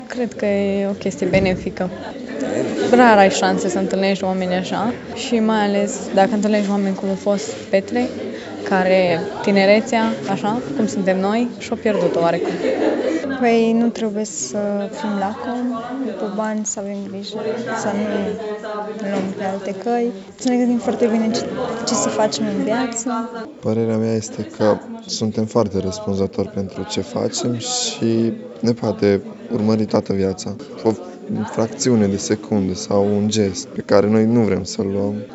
Studenții au ascultat cu ochii mari experiențele de viață ale deținuților și au plecat mai conștienți de consecințele pe care le-ar putea avea faptele lor: